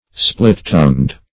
Search Result for " split-tongued" : The Collaborative International Dictionary of English v.0.48: Split-tongued \Split"-tongued`\ (spl[i^]t"t[u^]ngd`), a. (Zool.) Having a forked tongue, as that of snakes and some lizards.